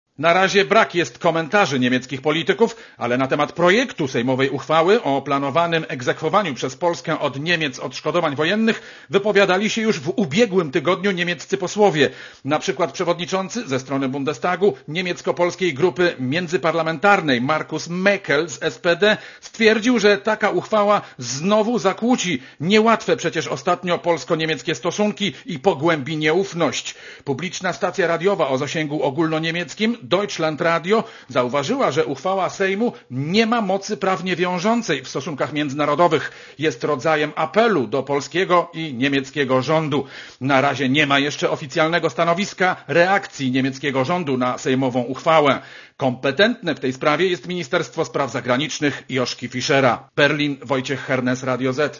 Korespondencja z Berlina